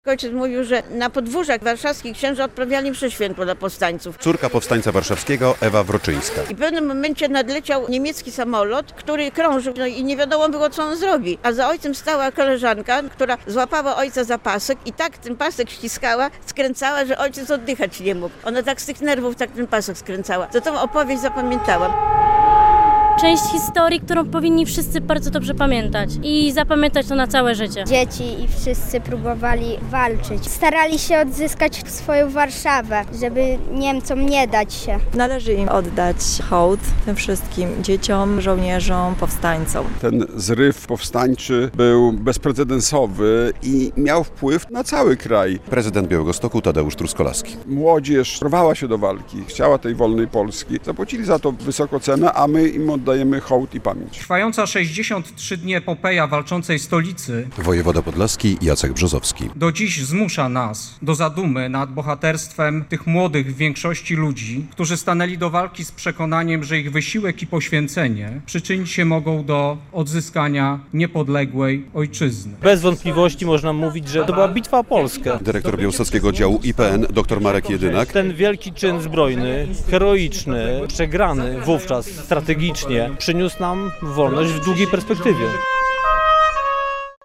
81. rocznica wybuchu Powstania Warszawskiego w Białymstoku - relacja